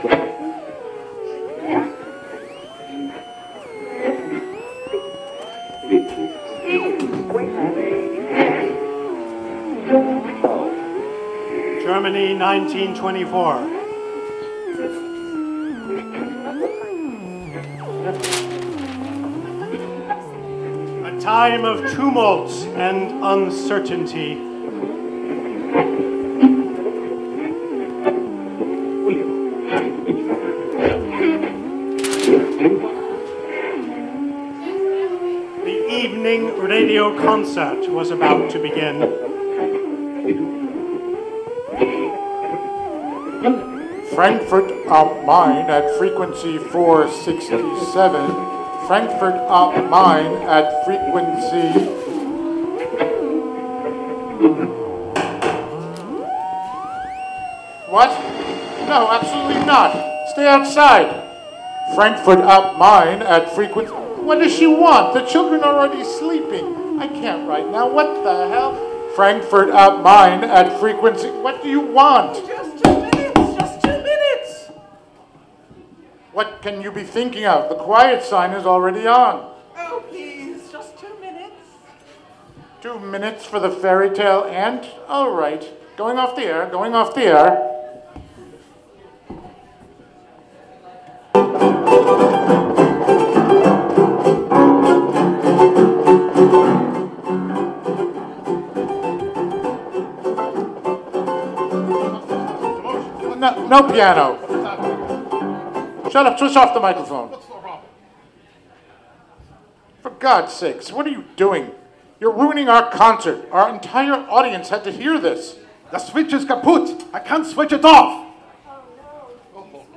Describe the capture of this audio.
Recorded live at HiLo in Catskill. Written in 1924, "Magic on the Radio" depicts chaos surrounding a radio broadcast under siege by conflicting interests and mysterious technical problems.